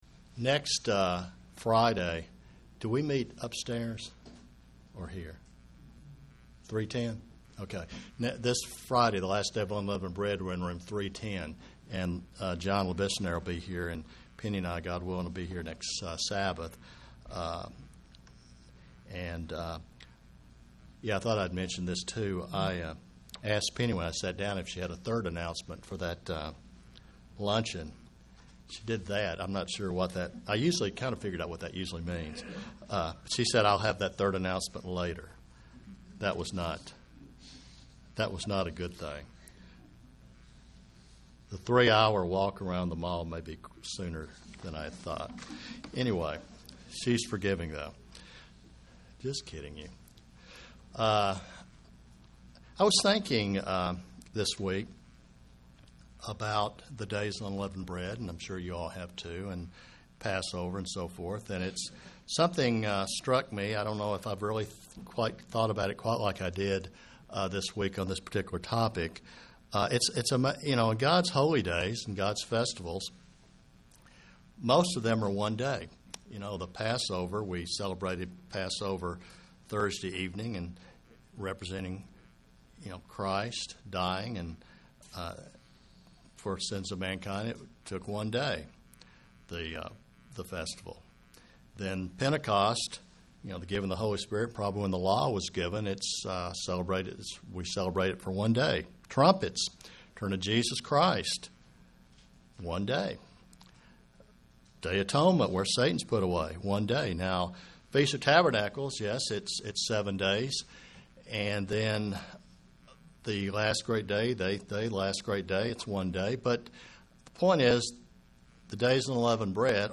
The Days of Unleavened Bread point a Christian to the continual need of obeying God and His word. (Presented to the Kingsport TN, Church)
Sermons